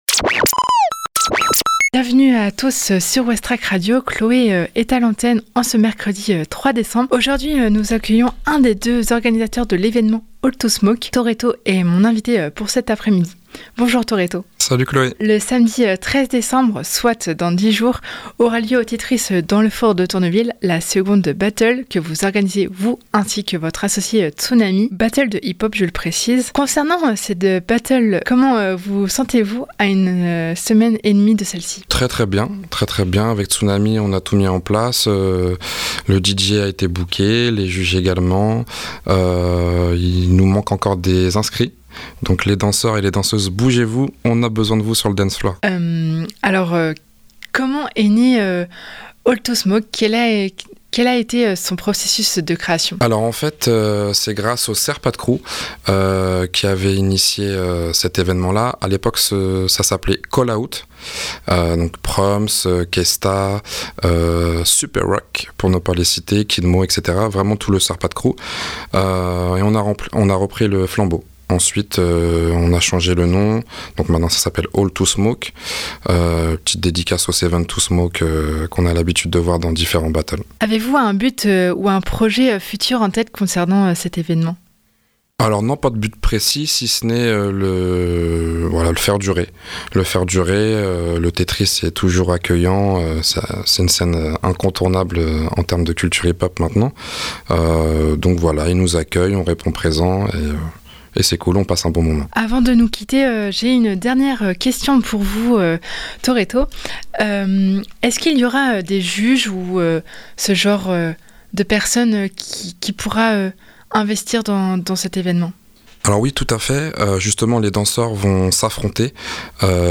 Plus d'information dans cette interview